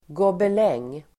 Ladda ner uttalet
Uttal: [gåbel'eng:]